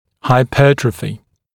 [haɪ’pɜːtrəfɪ][хай’пё:трэфи]гипертрофия